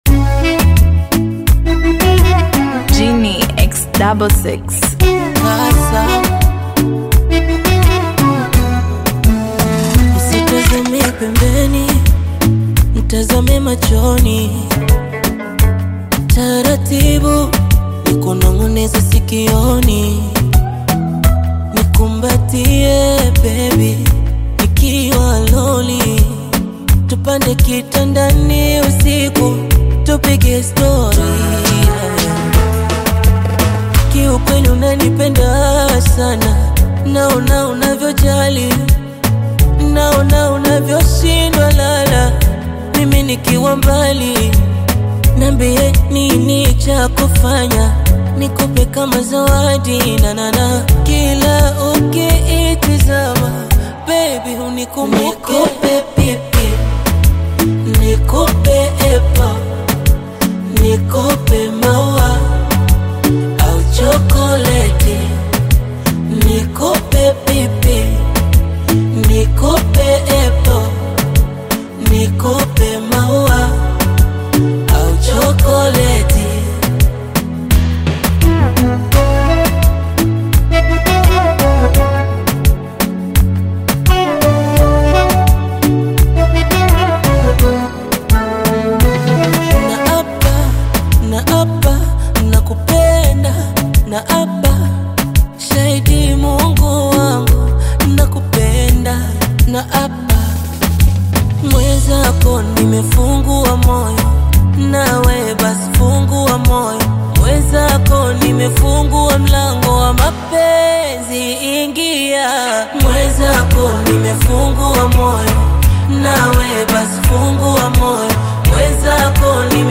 Latest Tanzania Afro-Beats Single (2026)
Genre: Afro-Beats